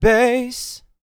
TB SING 101.wav